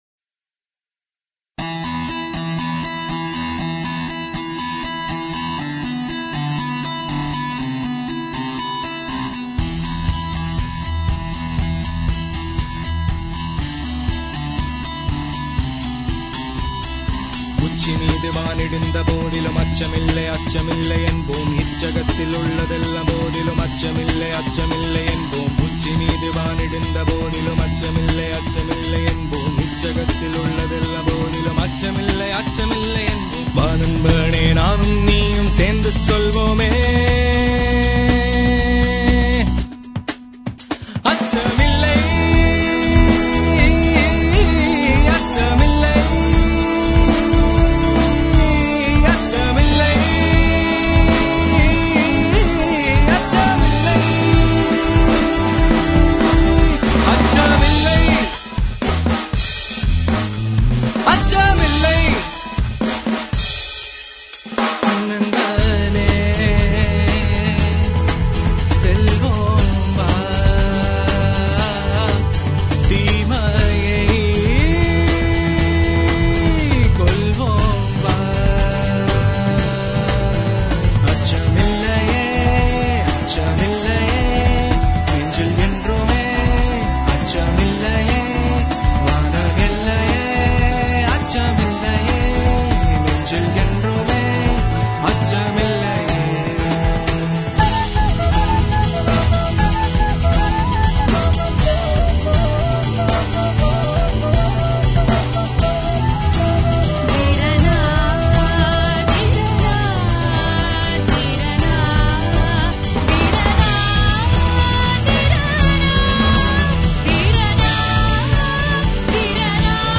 Lead Vocals
Flute